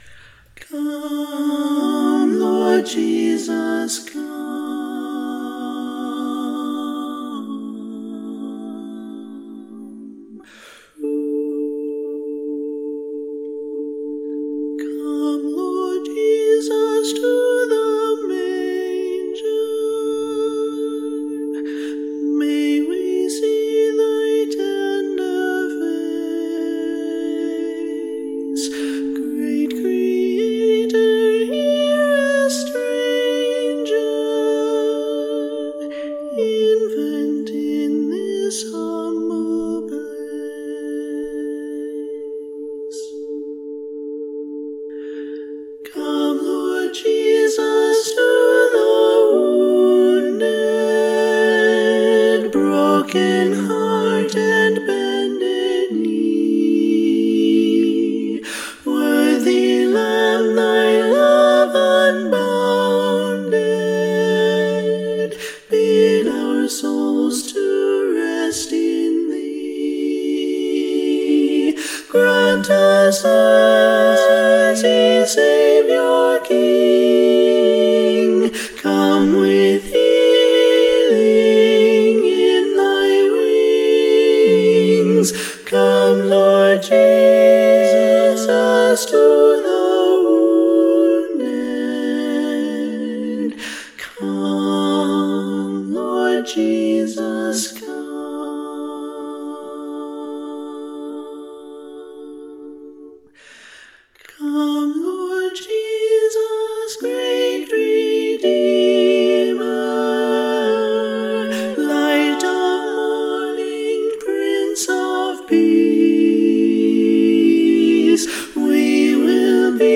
SSAA acappella arrangement
Voicing/Instrumentation: SSAA